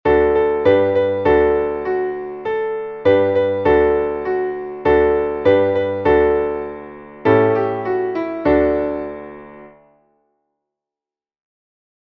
Traditionelles Volkslied